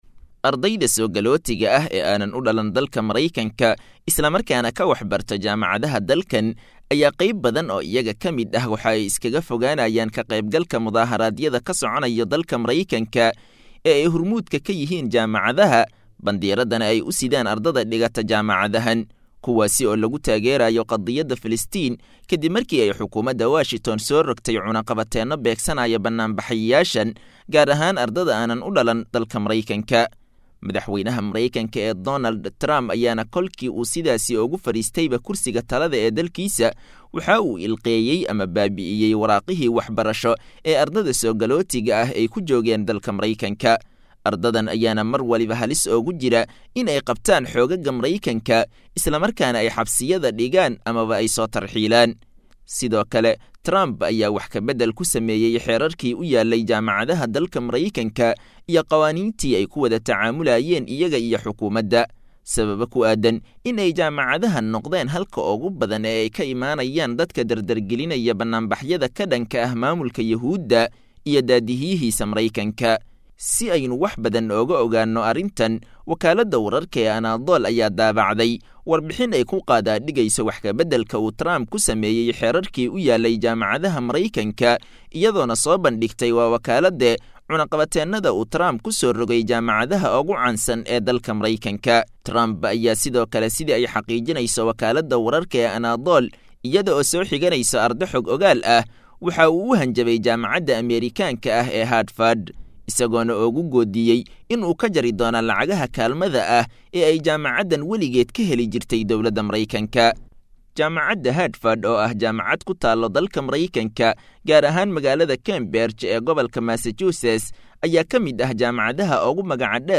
Tarxiilka Kasocda Mareykanka oo Afka Qabtay Ardayda Garabtaagan Muslimiinta Qazza.[WARBIXIN]